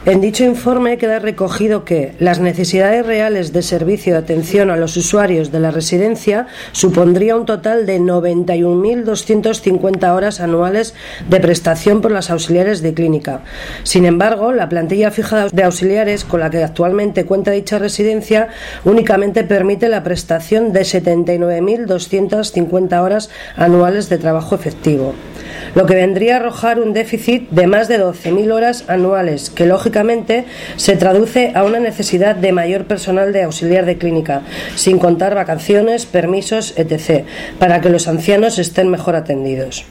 Rueda de prensa del Grupo Socialista ante las irregularidades detectadas por Inspección de Trabajo en la Residencia Nuestra Señora de Begoña